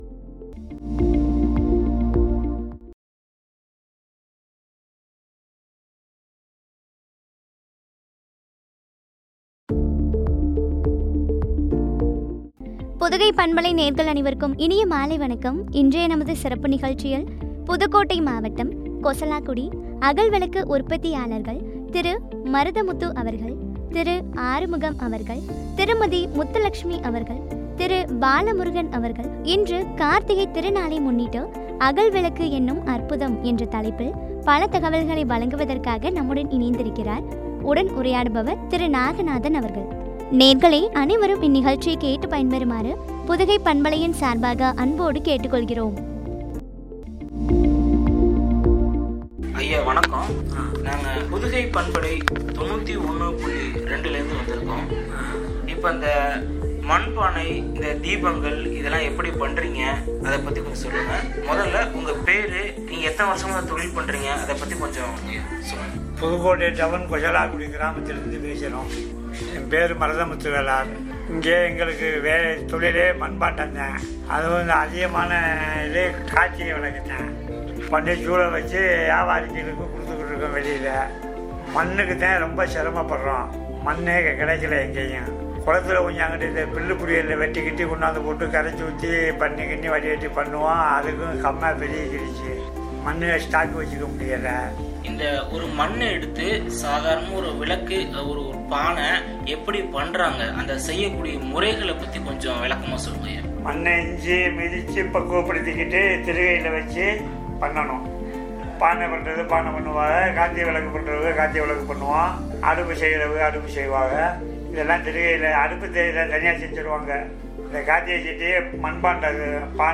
அகல்விளக்கு எனும் அற்புதம் என்ற தலைப்பில் வழங்கிய உரைகள்.